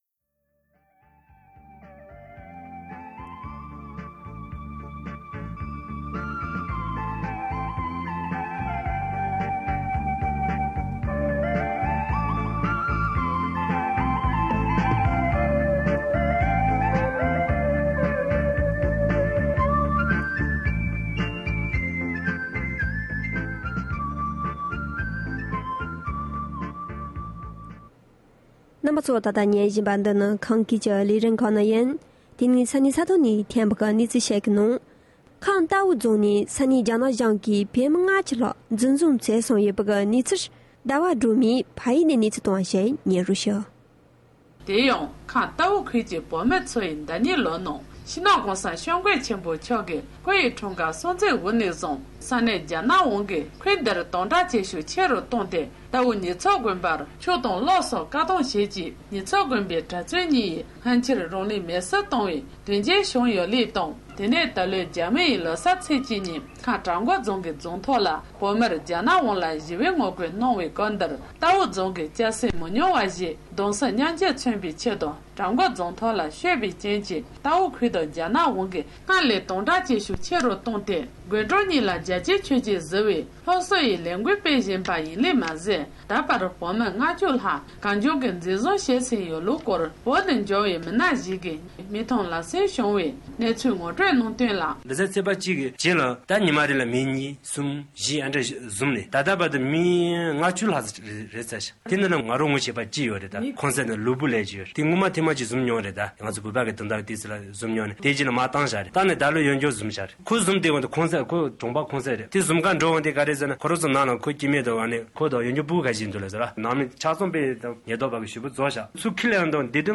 ཉེ་ཆར་ཁམས་རྟའུ་ནས་ཕེབས་པའི་བོད་མི་ཞིག་གིས
གསར་འགྱུར